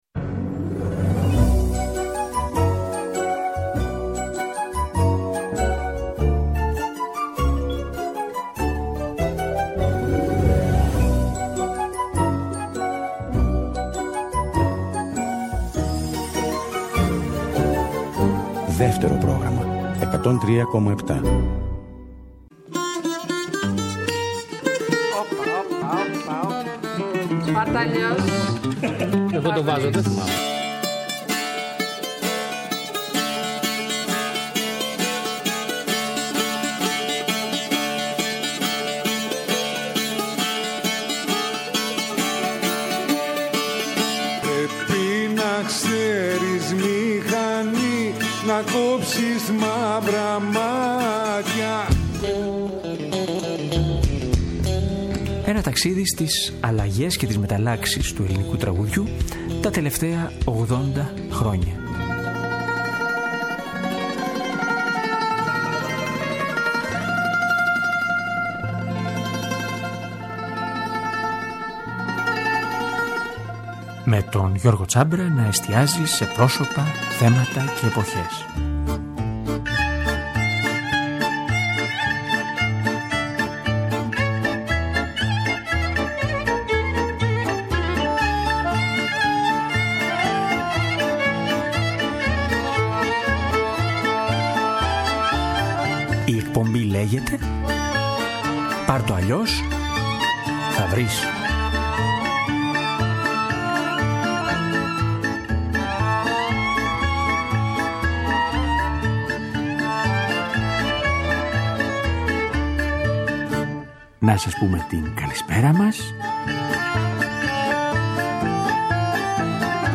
Παρασκευή 18/11/22, 9 με 10 το βράδυ στο Δεύτερο Πρόγραμμα.